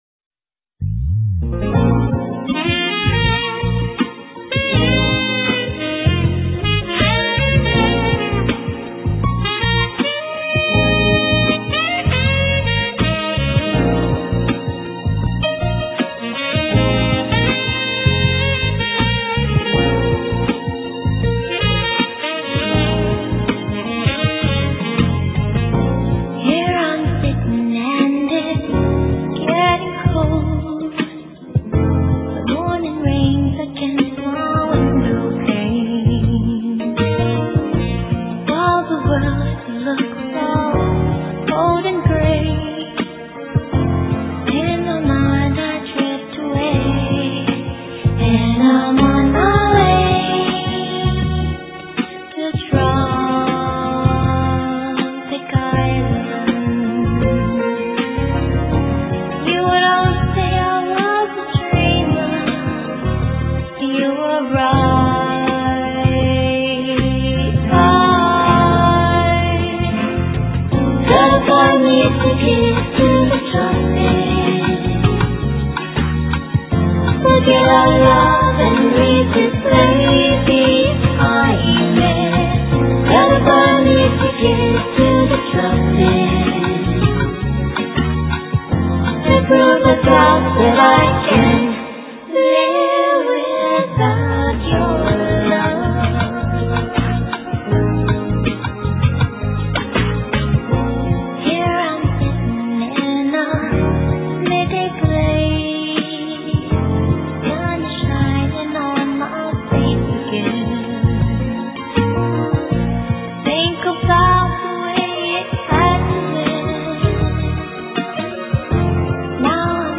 * Thể loại: Nhạc Ngoại Quốc